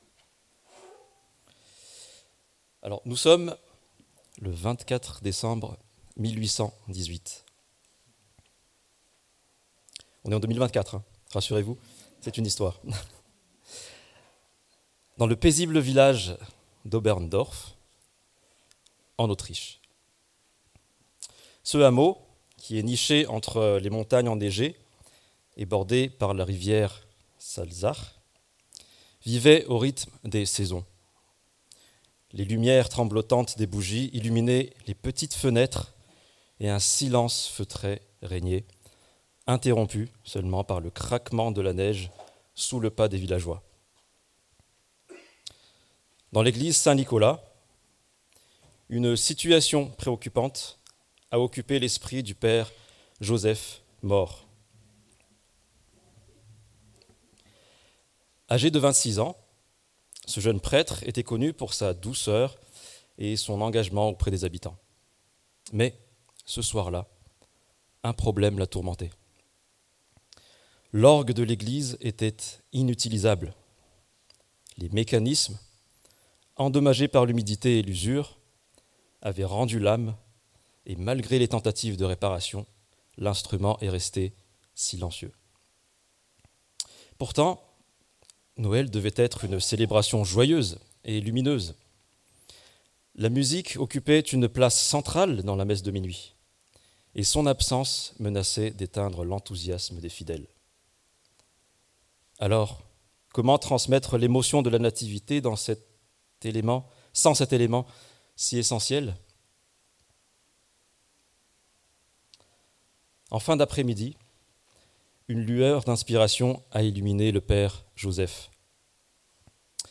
La paix, venue dans la simplicité - Prédication de l'Eglise Protestante Evangélique de Crest sur l'Evangile de Luc